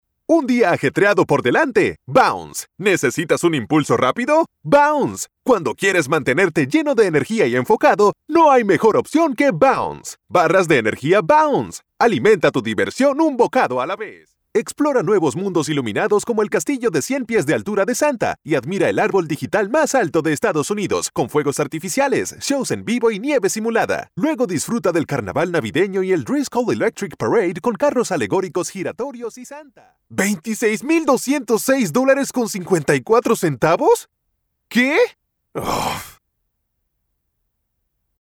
Male
Dominican Spanish (Native) Latin English (Accent) Neutral Latam Spanish (Native)
Fun.mp3
Acoustically treated studio.